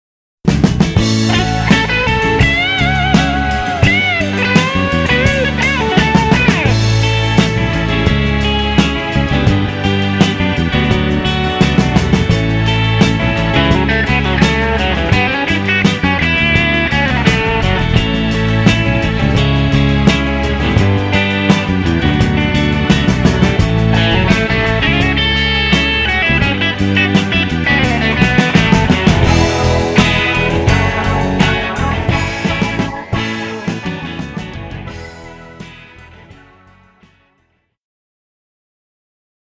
L’ABC de la guitare électrique pour débutants
Riffs, rythmiques, solos, arpèges, improvisation.